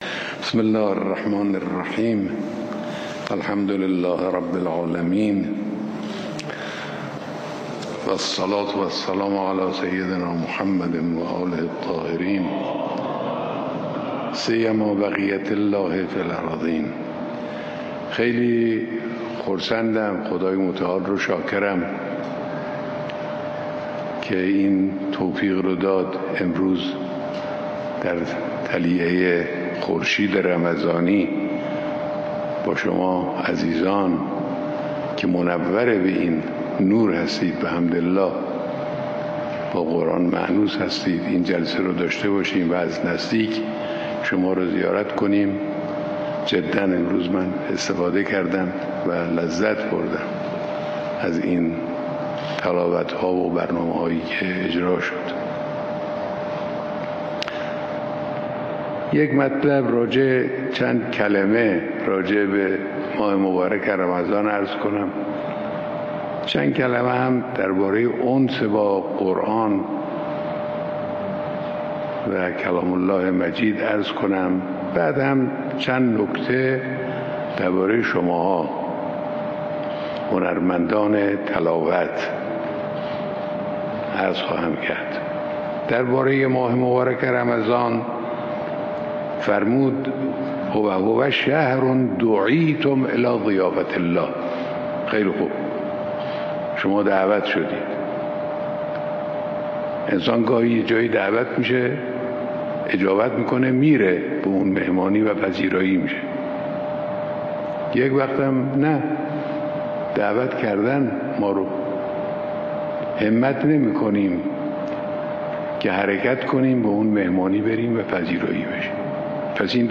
محفل انس با قرآن کریم در حضور رهبر انقلاب